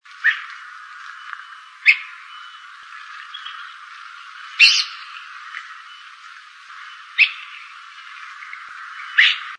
Creamy-bellied Thrush (Turdus amaurochalinus)
Location or protected area: Reserva Ecológica Costanera Sur (RECS)
Condition: Wild
Certainty: Photographed, Recorded vocal
Recs.Zorzal-blanco.mp3